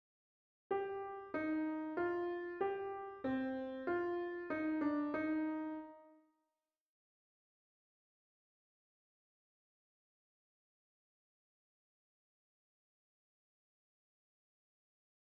Pour commencer voici le thème au début, exposé par la première voix. Ce thème est appelé le sujet de la fugue.